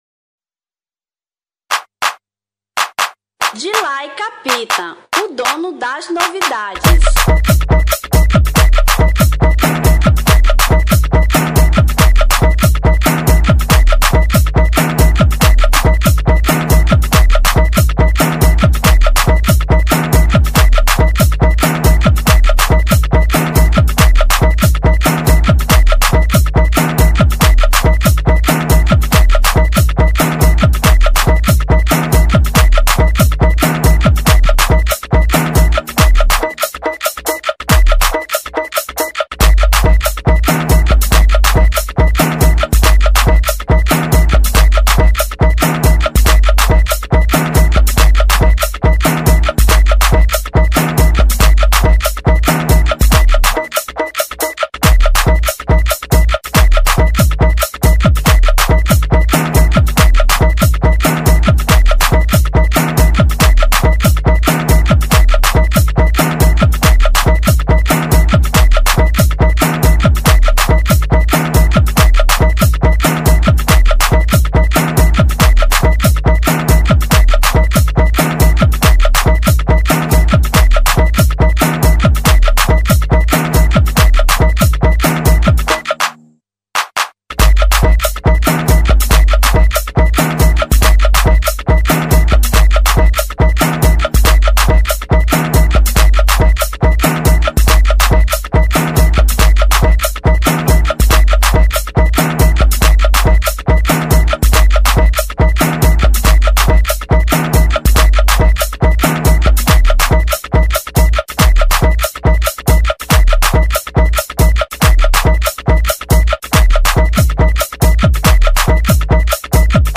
Instrumental 2006